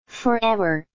美音 (us)